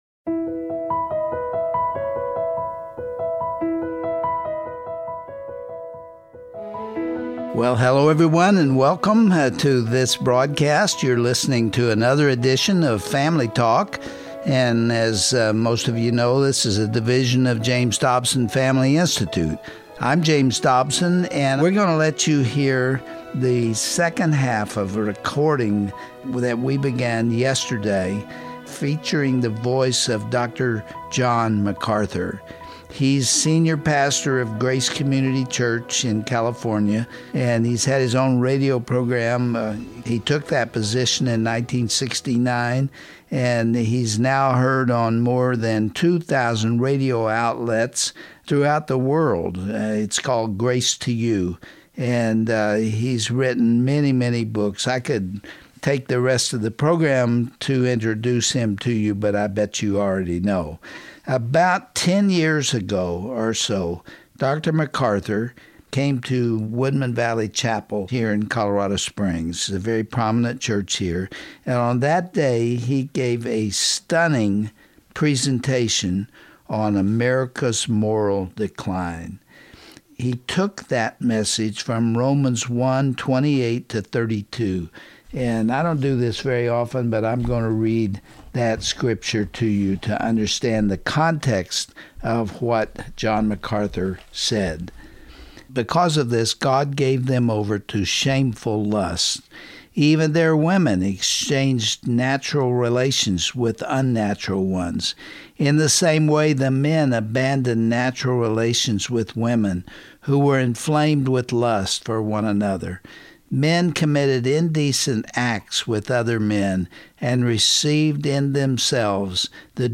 According to Pastor John MacArthur, there is no greater indicator of a corrupt and abandoned society than when that society will not tolerate anger against sin. On today’s edition of Family Talk, John concludes his moving message about the Lord’s wrath on America.